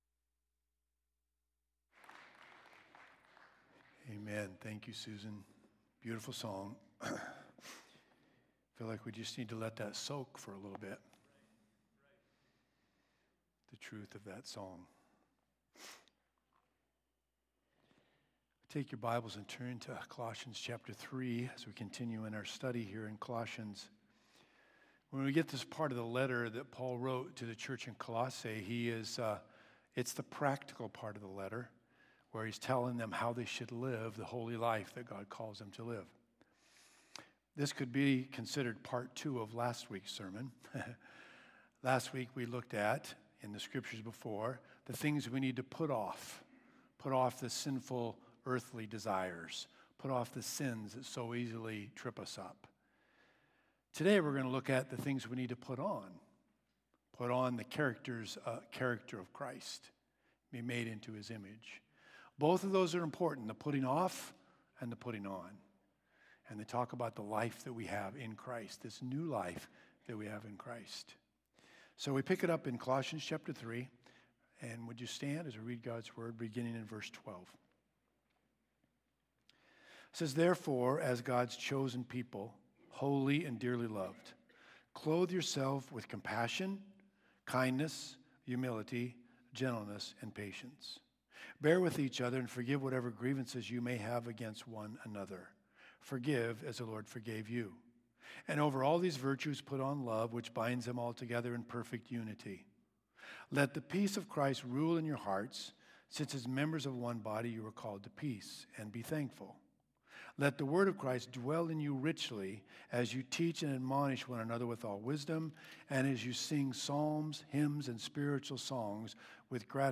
Subscribe to sermon Podcast RSS